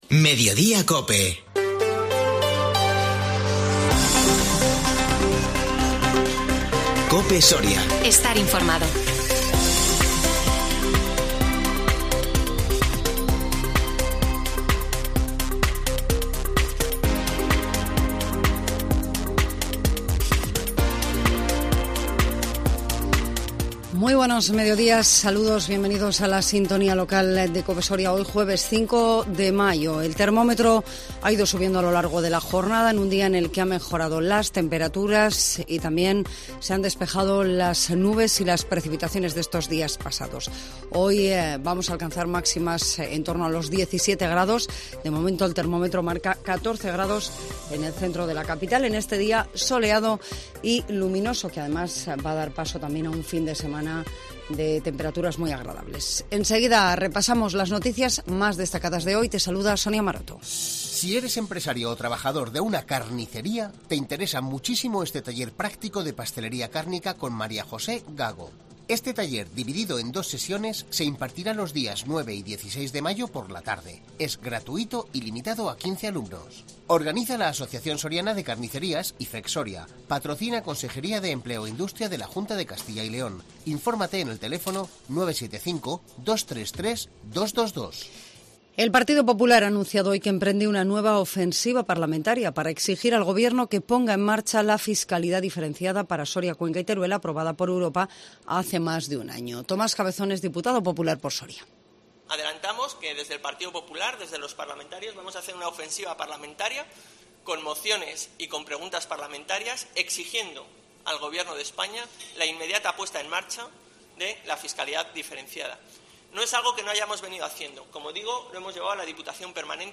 INFORMATIVO MEDIODÍA COPE SORIA 5 MAYO 2022